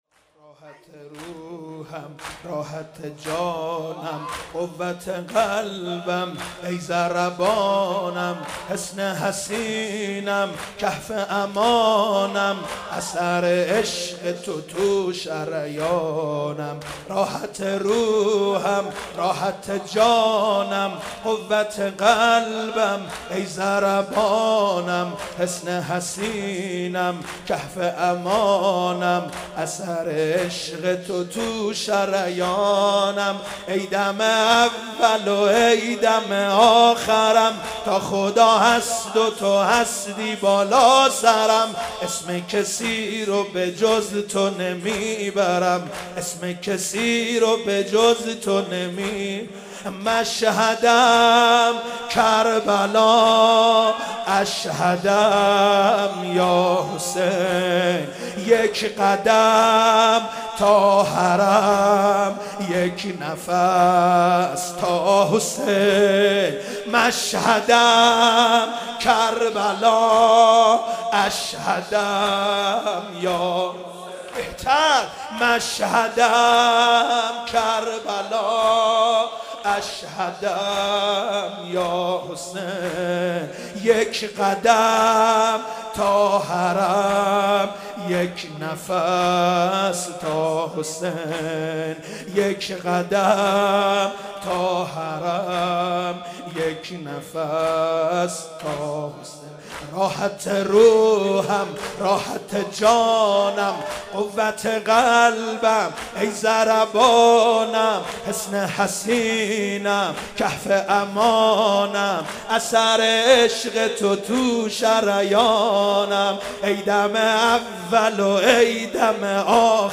زمینه ، سال 94،جدید